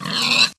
sheep.ogg